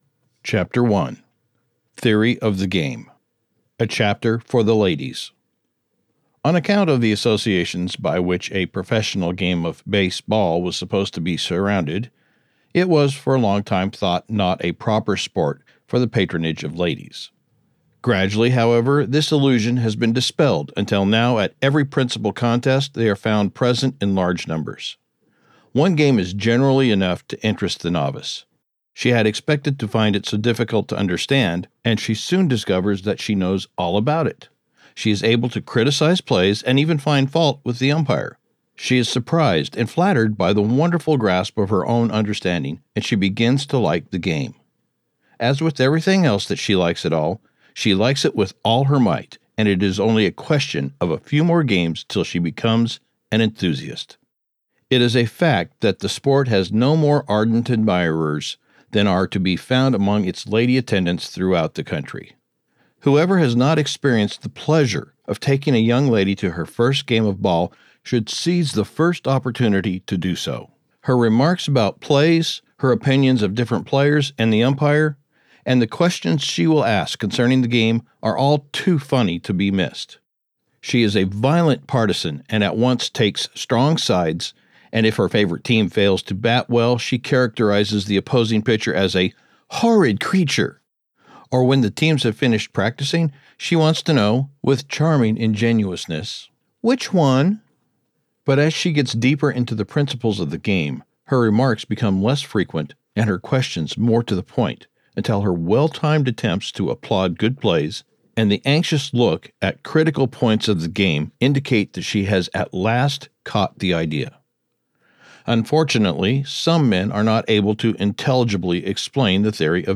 Audiobook production Copyright 2025, by GreatLand Media.